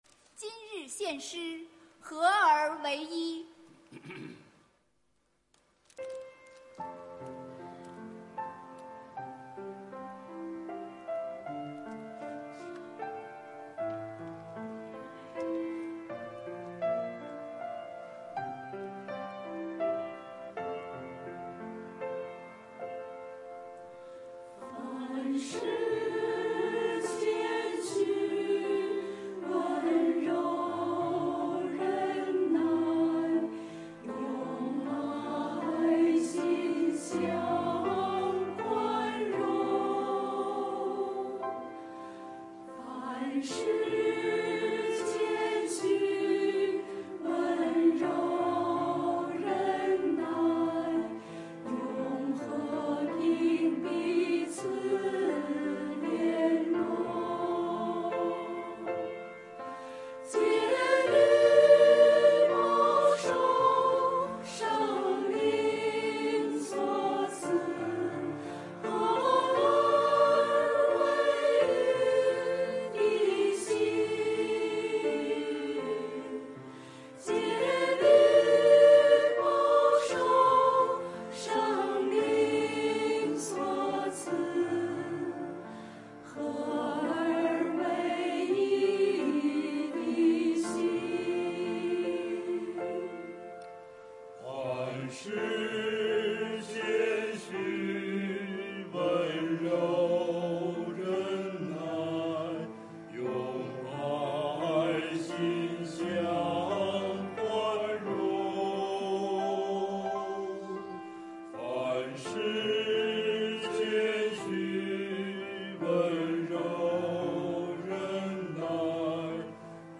团契名称: 联合诗班 新闻分类: 诗班献诗 音频: 下载证道音频 (如果无法下载请右键点击链接选择"另存为") 视频: 下载此视频 (如果无法下载请右键点击链接选择"另存为")